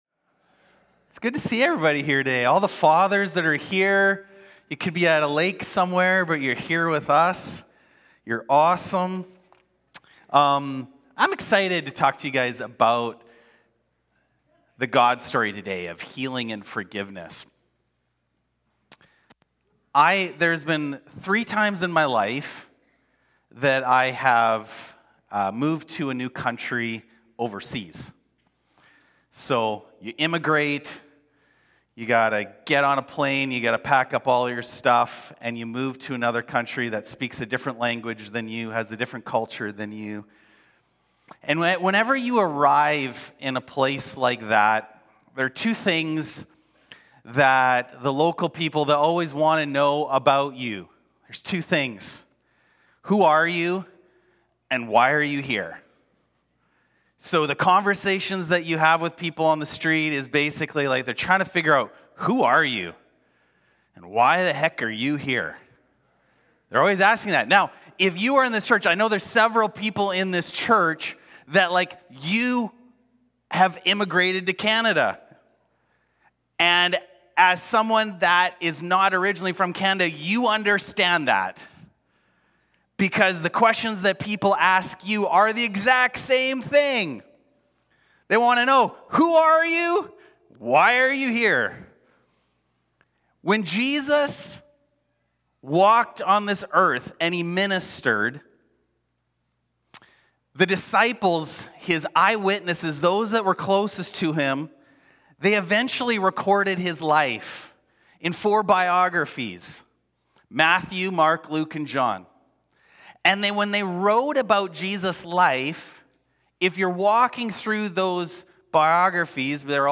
Sermons | Gateway City Church